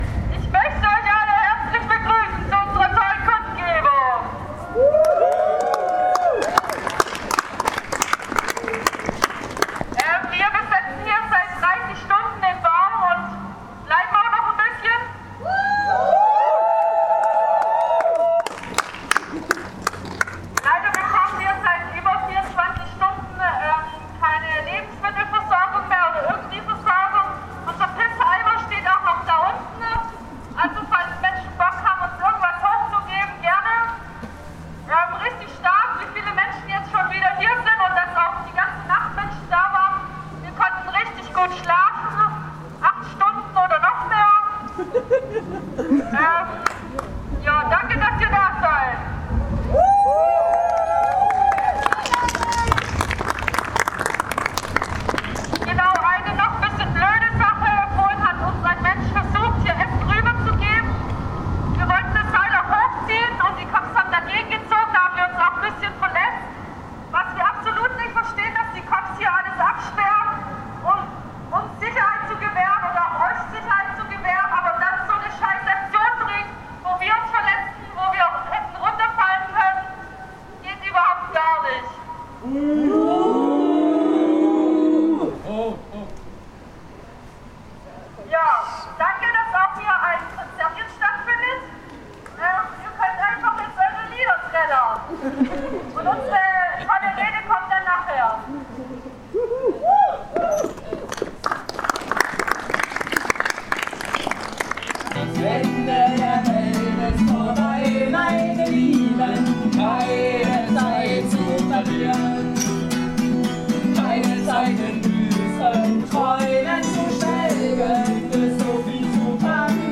Auch musikalische Unterstützung war vorhanden.